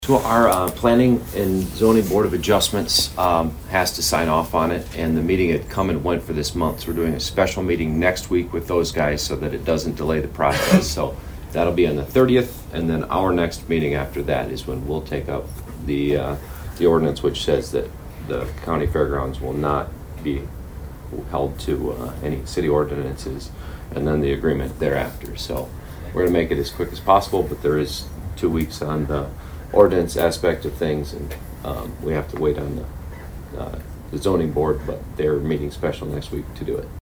Mayor Schaunaman discuss the timeline for the City of Aberdeen.